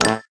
SFX_Menu_Button.ogg